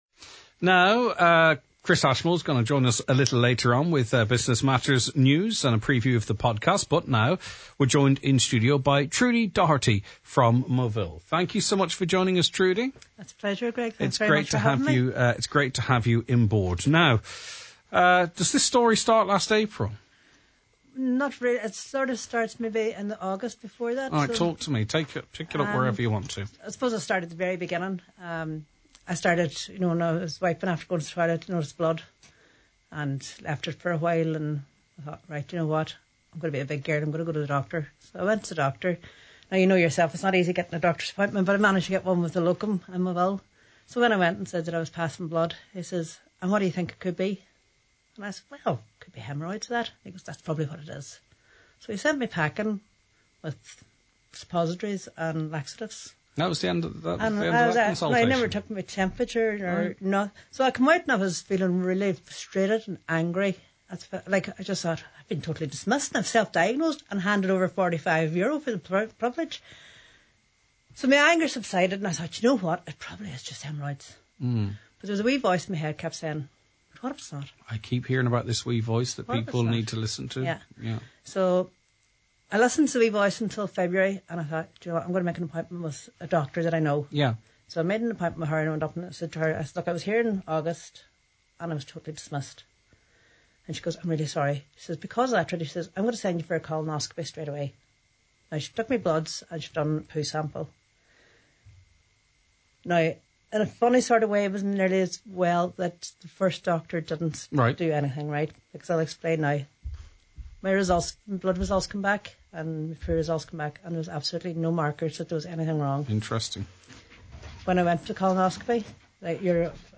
The Nine Til Noon Show is broadcast live weekdays 9am til 12noon!